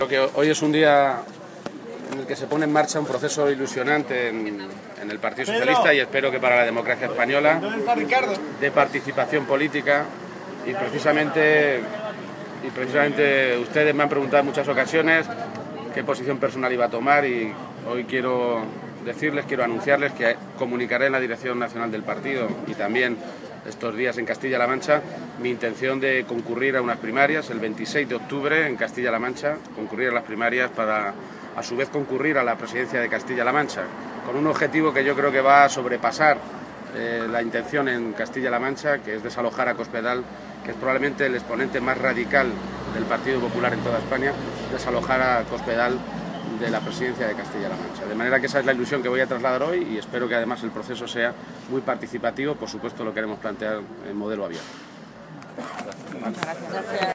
García-Page se pronunciaba de esta manera minutos antes de comenzar el Comité Federal del PSOE, en Madrid, una reunión en la que se fija el calendario para las elecciones primarias que elegirán los candidatos a las comunidades autónomas y ayuntamientos, elecciones que se desarrollarán entre los meses de octubre y noviembre.
Cortes de audio de la rueda de prensa